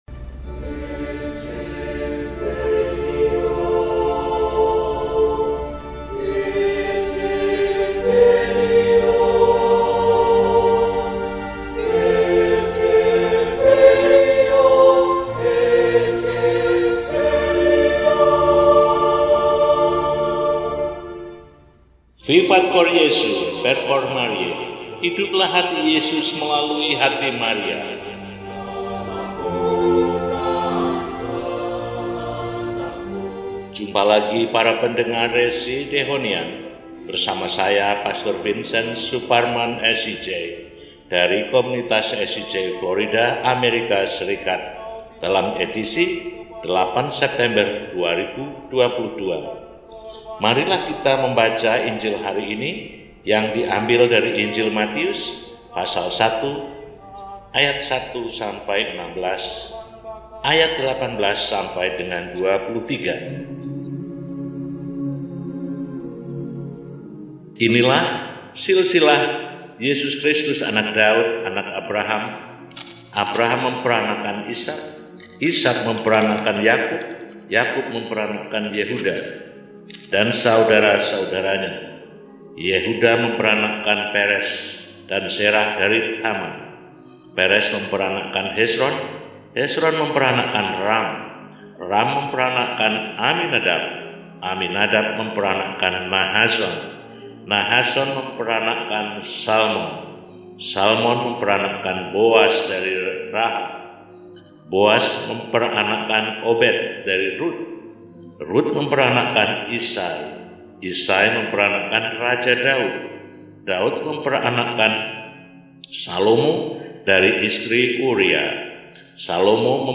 Kamis, 08 September 2022 – Pesta Kelahiran Santa Perawan Maria – RESI (Renungan Singkat) DEHONIAN